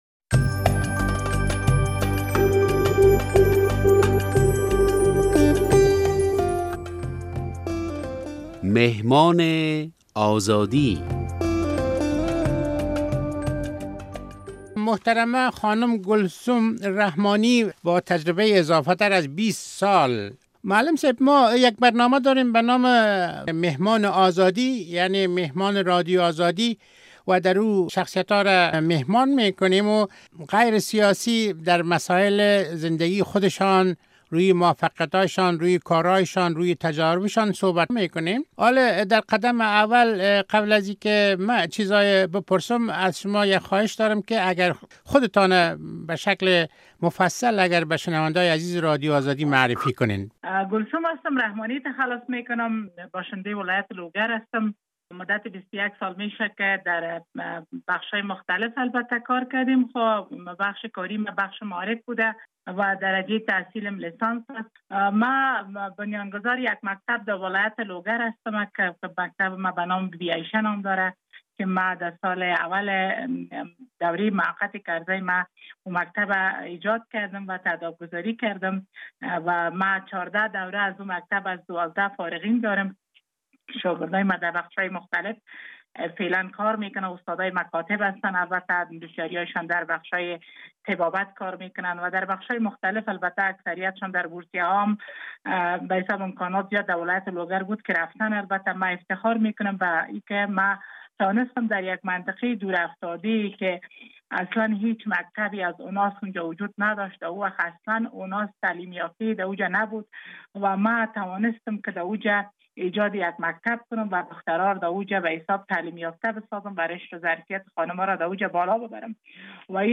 در صحبت با برنامهٔ « مهمان آزادی»